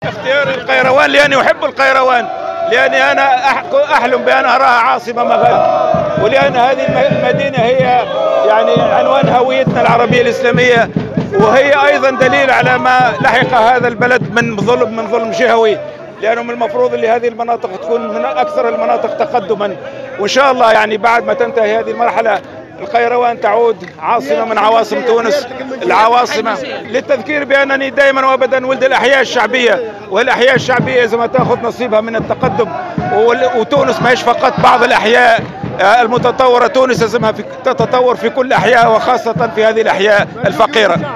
أدى اليوم المترشح للانتخابات الرئاسية،المنصف المرزوقي زيارة إلى ولاية القيروان وذلك في إطار حملته الانتخابية.